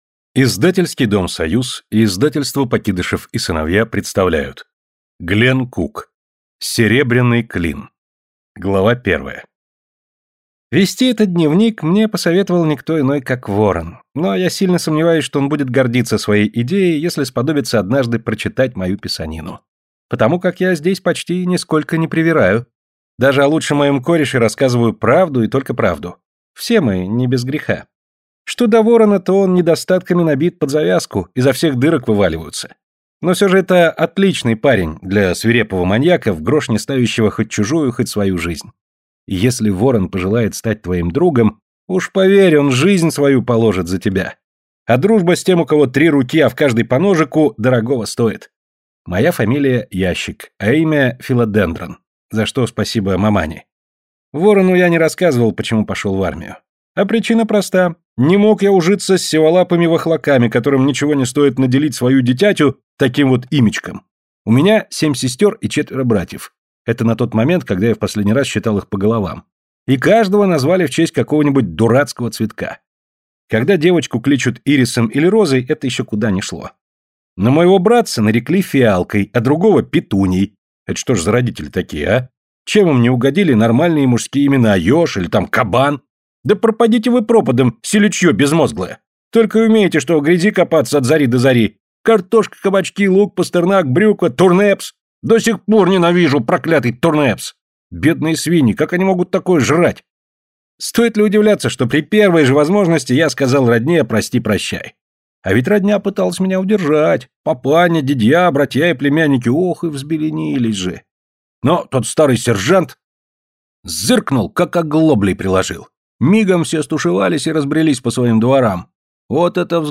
Аудиокнига Серебряный Клин | Библиотека аудиокниг